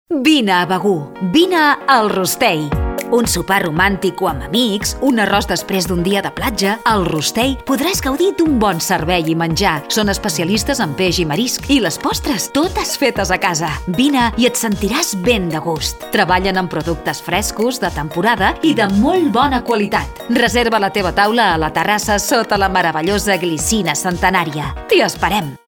Anunci-Restaurant-Rostei.mp3